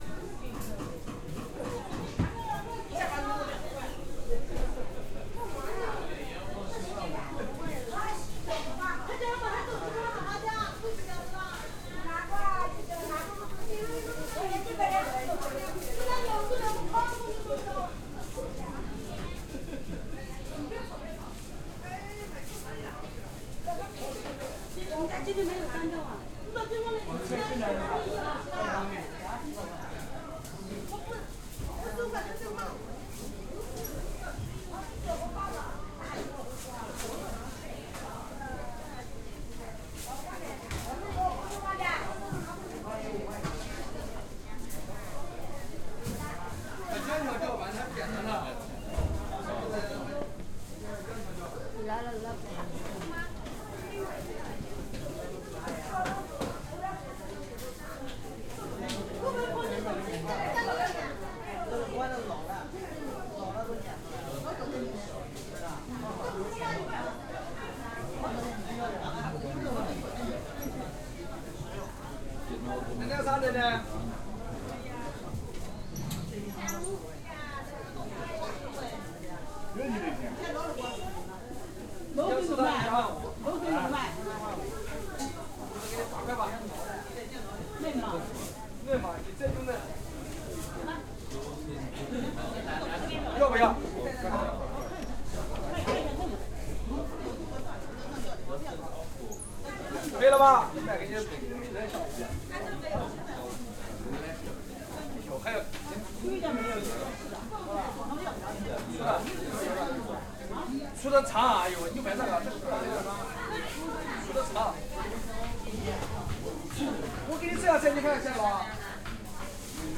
SHANGHAI walla interior market vegetables meat fish lively close voices chinese plastic bags chopping.ogg